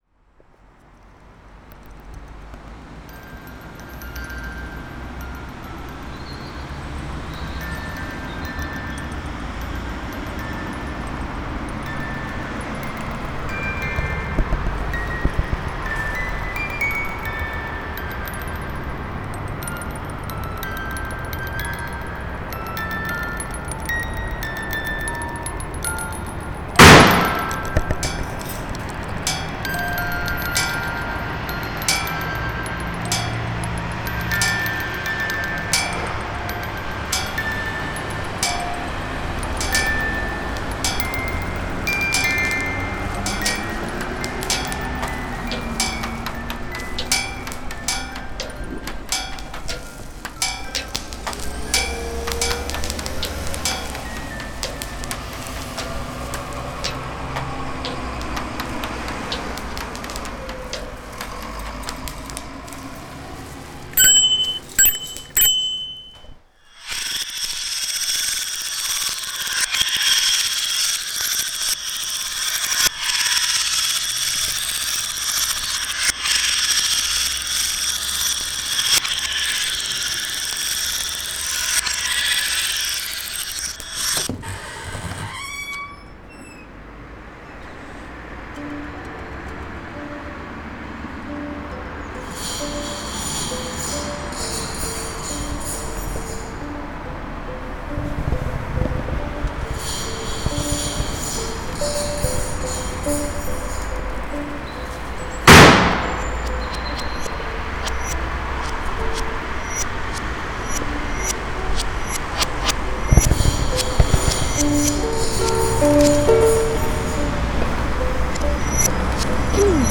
Petites fictions, ambiances propres au campus, paroles relatives à la vie universitaire, le tout dans des ambiances un rien décalées... les vignettes sonores vous proposent une "audio-vision" ludique du campus.
13 - Sifflements
14 - Serinette.mp3